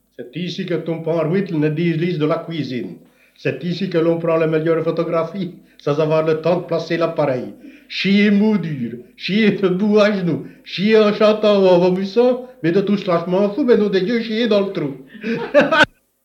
Genre : chant
Type : chanson narrative ou de divertissement
Interprète(s) : Anonyme (homme)
Lieu d'enregistrement : Morville
Support : bande magnétique